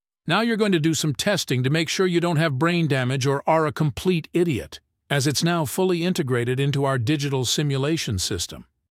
anouncer_intro_04.ogg